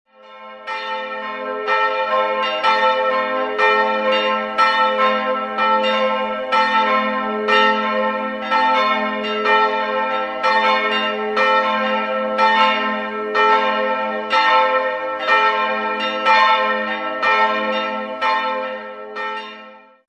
Jahrhunderts wurde das Gotteshaus nach Westen hin verlängert. 3-stimmiges A-Moll-Geläute: a'-c''-e'' Die Glocken wurden 1949 von Petit&Edelbrock in Gescher (Westfalen) gegossen.